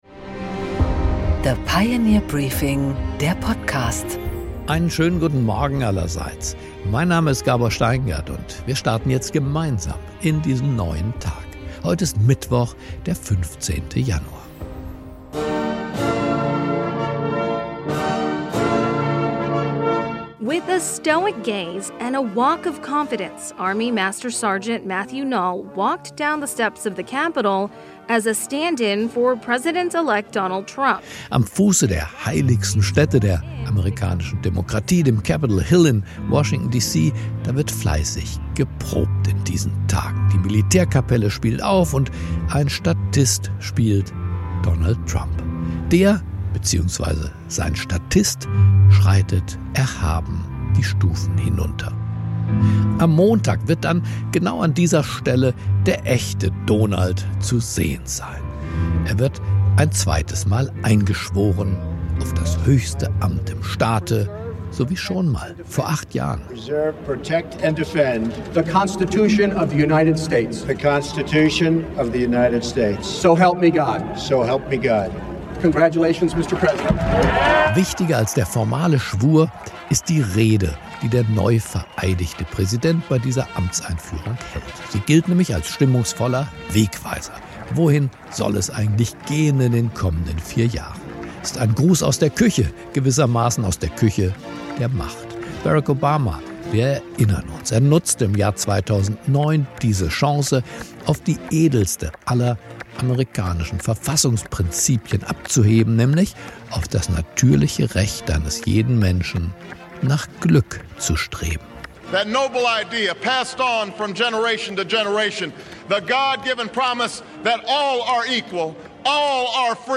Gabor Steingart präsentiert das Pioneer Briefing
Interview